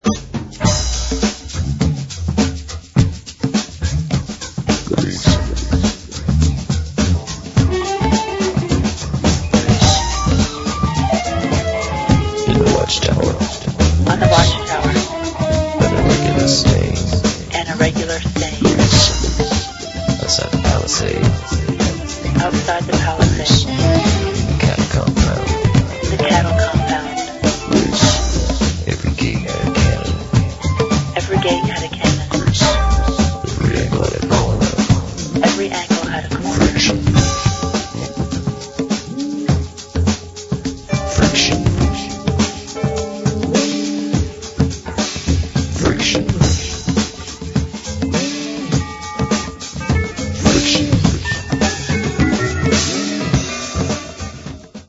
abstract art-funk